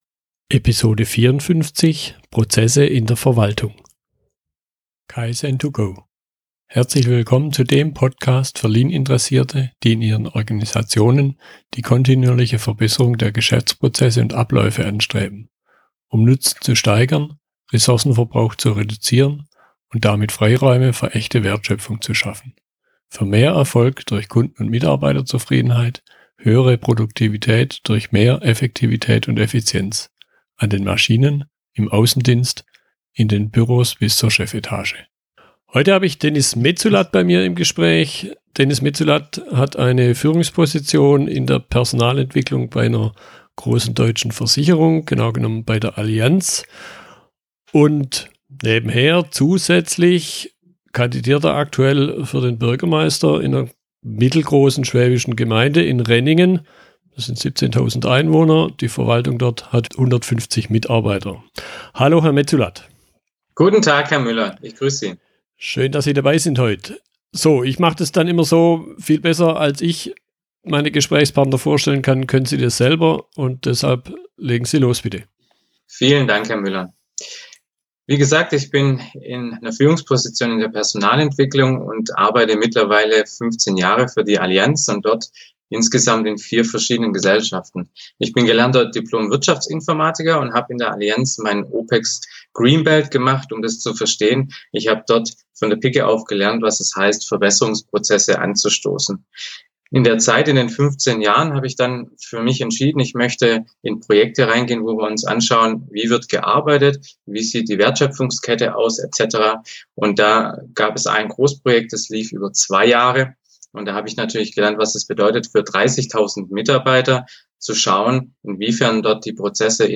Wir unterhalten uns darüber, was Prozesse und deren Verbesserung in der Verwaltung und Wirtschaft gemeinsam haben und wo Unterschiede bestehen.
Im Gespräch